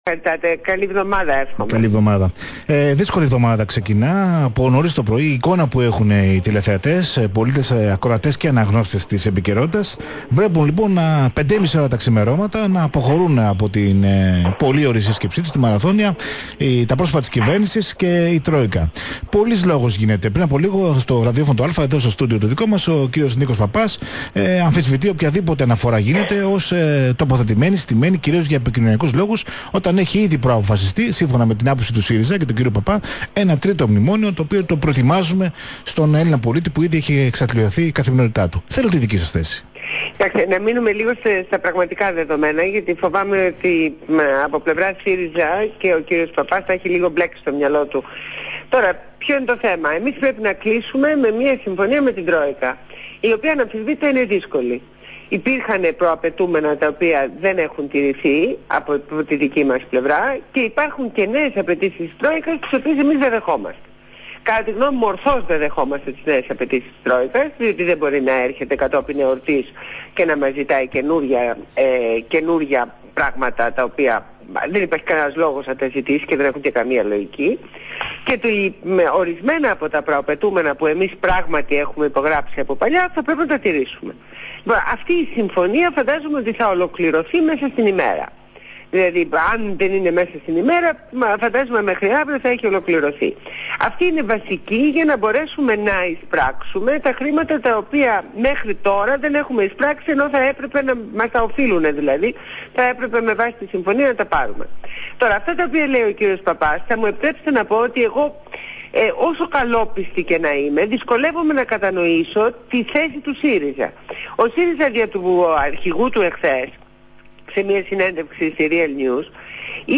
Ακολουθεί η απομαγνητοφώνηση της συνέντευξης: